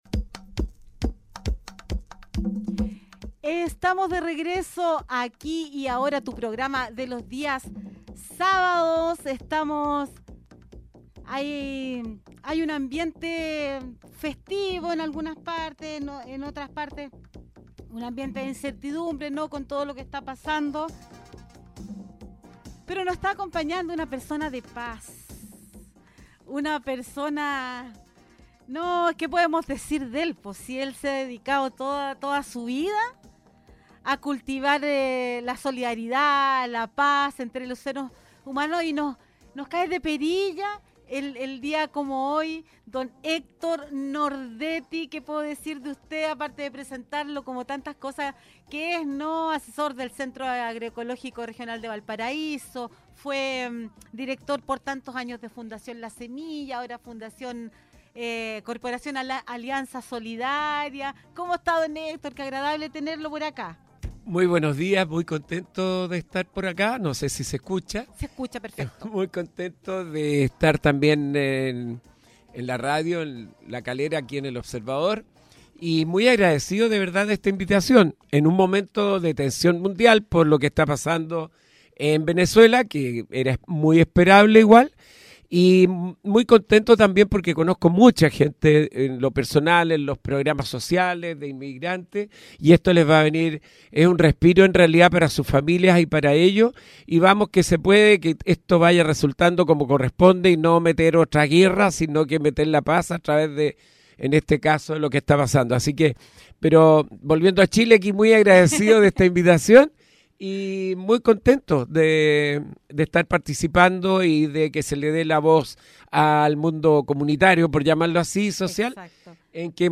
En entrevista con Radio Observador 105.5 FM